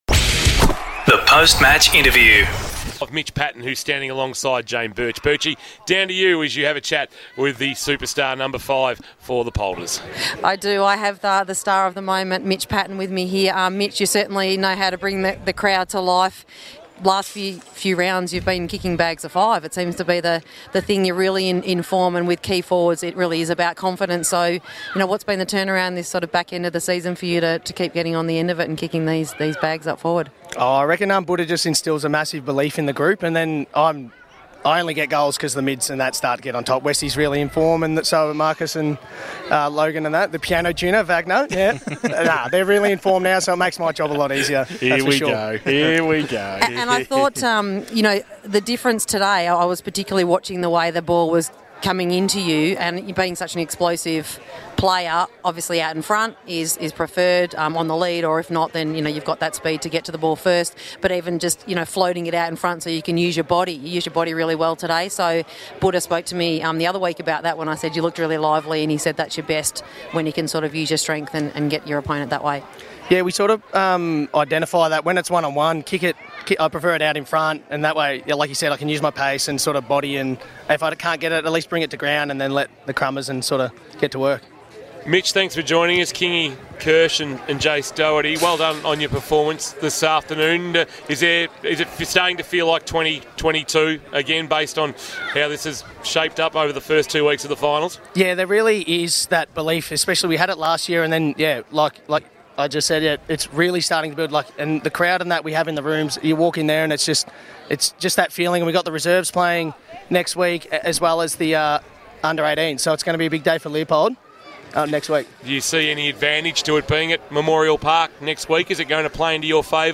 2023 - GFNL - 1ST SEMI FINAL - COLAC vs. LEOPOLD: Post-match interview